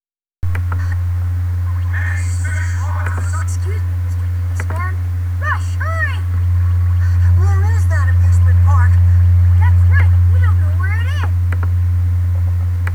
Kinda bad quality, and me jumping around with the DVD, that's why it skips to one thing and then another.
Oh, and the other voice that sounds like a kid is Mega Man.
rushvocalsample.ogg